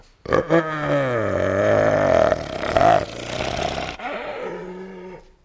c_hyena_bat1.wav